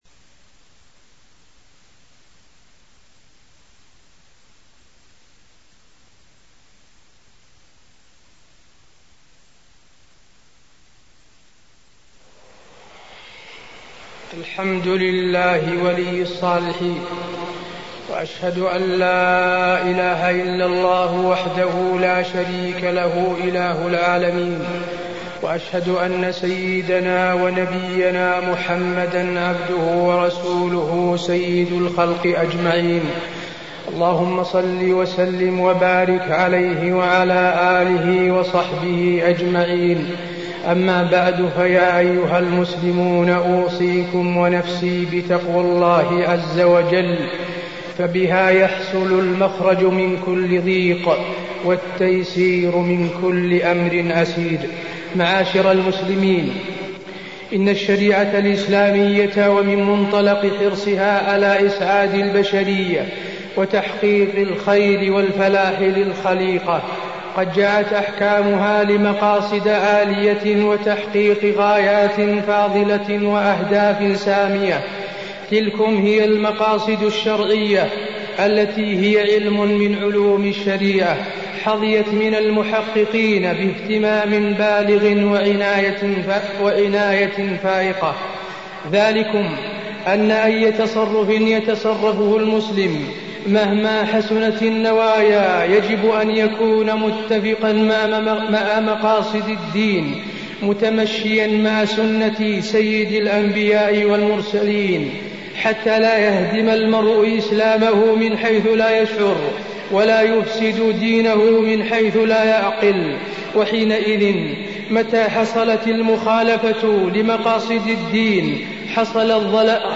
تاريخ النشر ١٨ ربيع الأول ١٤٢٥ هـ المكان: المسجد النبوي الشيخ: فضيلة الشيخ د. حسين بن عبدالعزيز آل الشيخ فضيلة الشيخ د. حسين بن عبدالعزيز آل الشيخ الأحداث